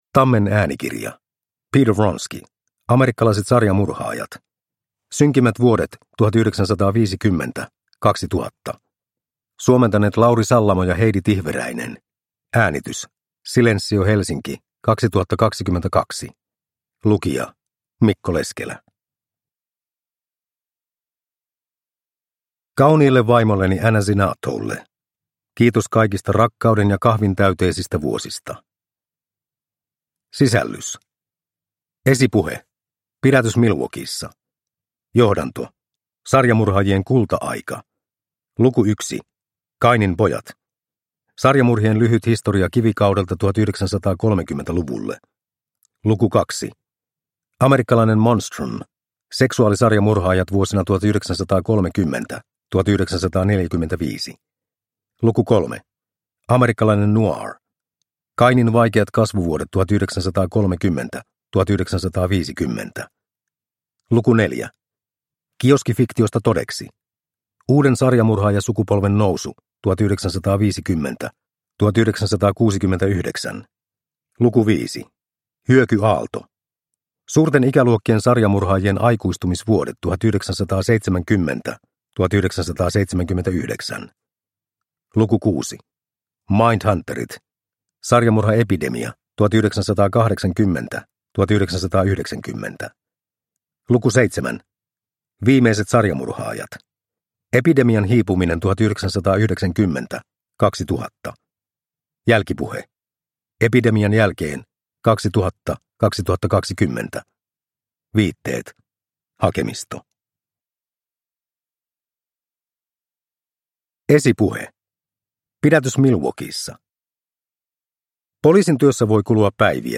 Amerikkalaiset sarjamurhaajat (ljudbok) av Peter Vronsky